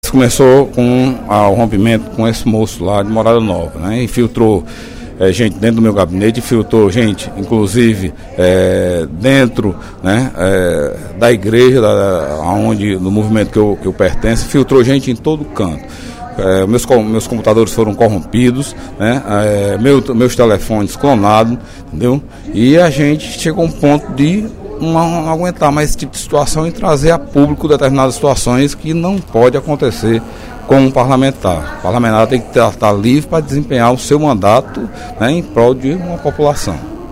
O deputado Delegado Cavalcante (PDT) disse, durante o primeiro expediente da sessão plenária desta quarta-feira (12/06), que está sendo vítima de perseguição por parte do prefeito de Morada Nova, Glauber Lacerda.
O deputado Antonio Carlos (PT), também em aparte, disse que os parlamentares têm de abominar qualquer tipo de perseguição ou espionagem.